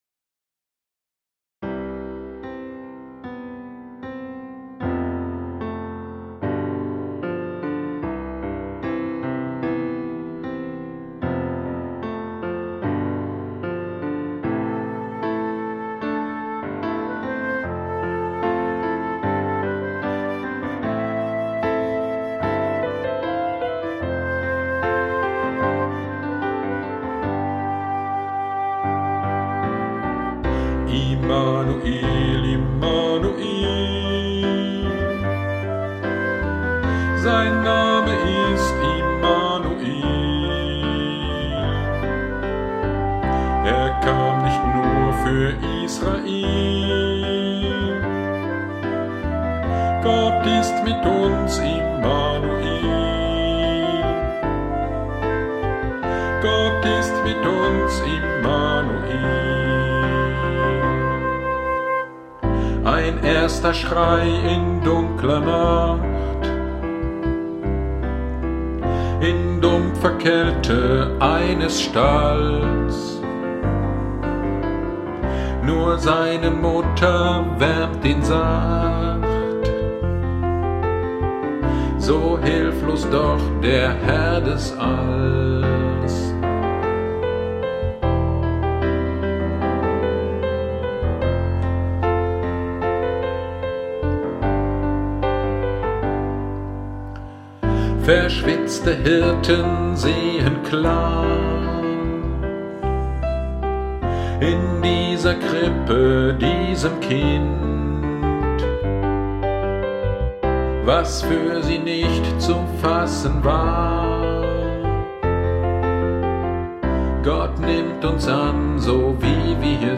GD am 12.01.2025 Predigt zu Psalm 4,4